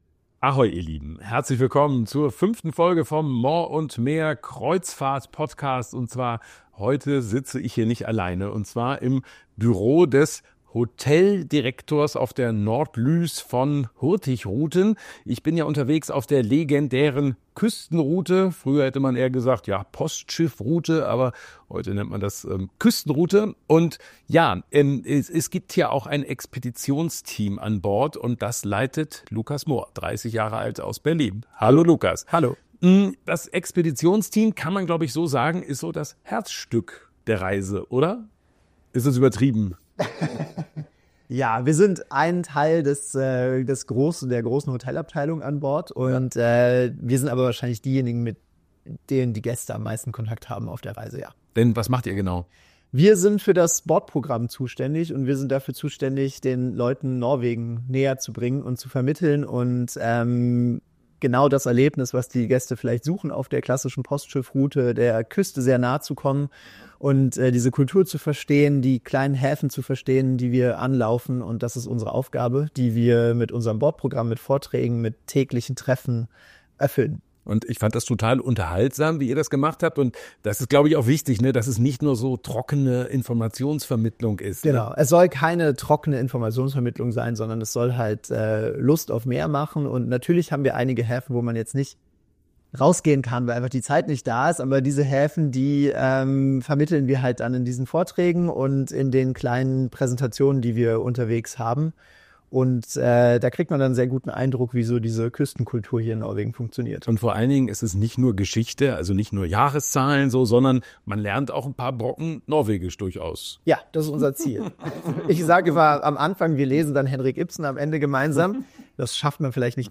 Morr & Meer - Kreuzfahrt-Podcast Faszination Postschiffe - Unterwegs mit Hurtigruten #5 Play episode August 24 48 mins Bookmarks Episode Description dieser Folge bin ich an Bord der MS Nordlys unterwegs – auf der klassischen Postschiffroute entlang der norwegischen Küste.